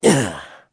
Crow-Vox_Landing.wav